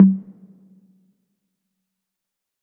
6CONGA 2HI.wav